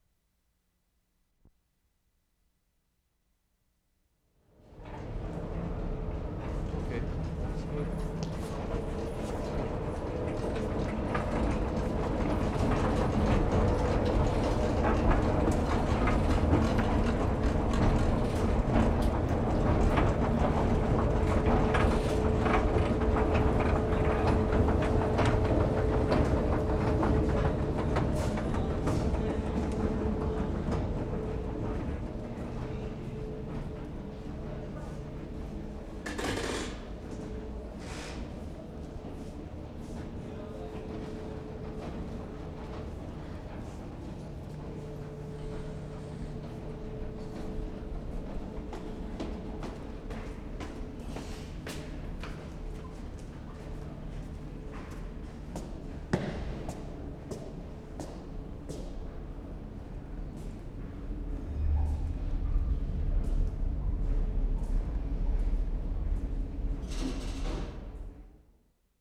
TORONTO, ONTARIO Nov. 3, 1973
SUBWAY ESCALATOR 1'00"
4. Broadband hum with clatter of escalator. Turnstile clatter (0'30"), footsteps going upstairs (0'50").